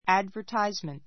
advertisement A2 ædvərtáizmənt ア ド ヴァ タ イ ズ メン ト ｜ ədvə́ːtismənt ア ド ヴァ ～ティ ス メン ト 名詞 （新聞・ポスター・テレビ・インターネットなどによる） 広告, 宣伝 ⦣ 話 では ad と短縮されることがある.